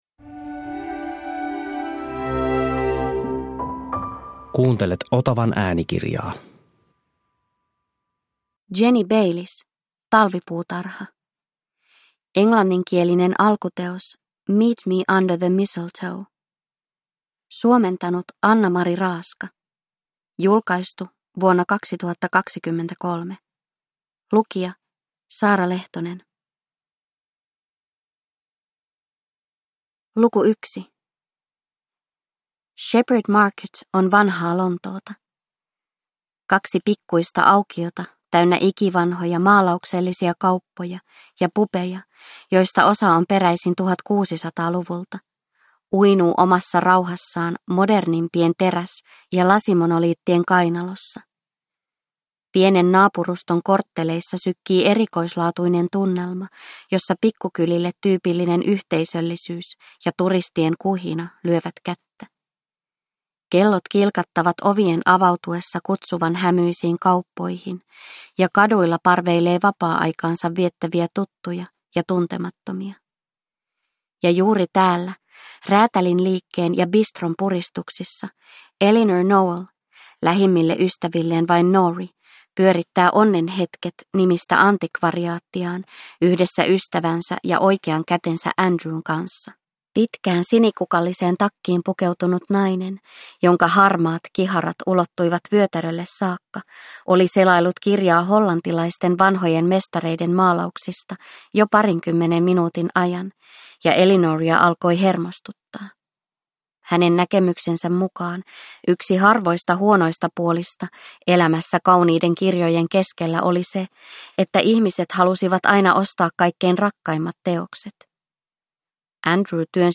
Talvipuutarha – Ljudbok – Laddas ner